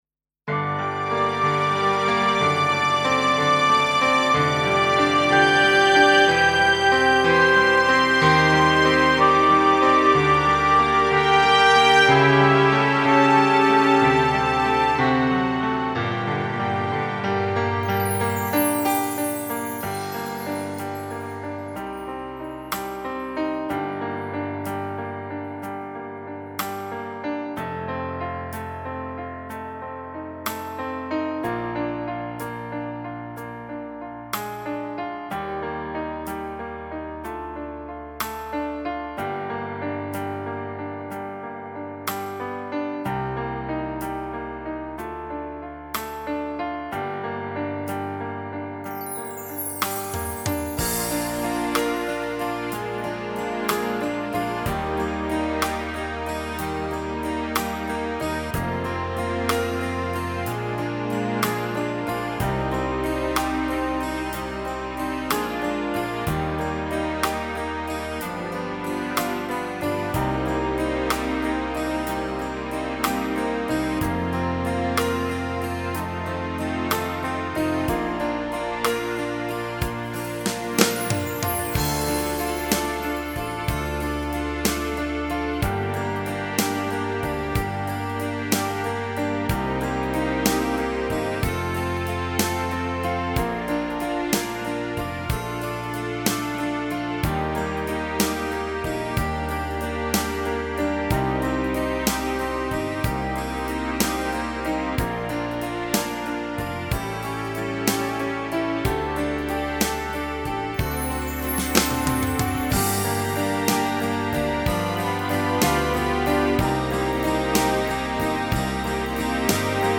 •   Beat  01.